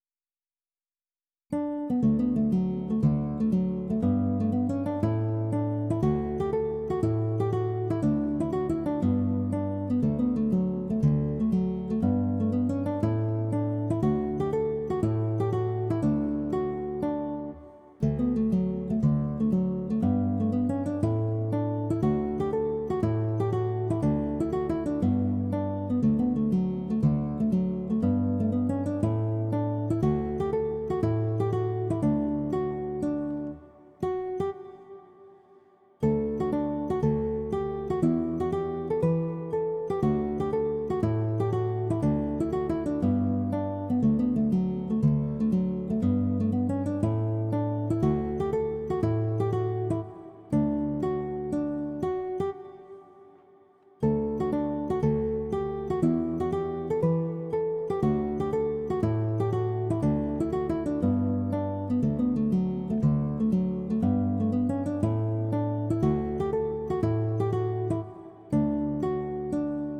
The Boys of Bluehill – Easy Picking